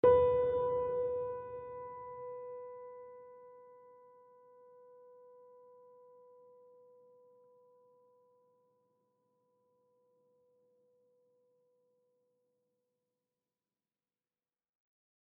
GreatAndSoftPiano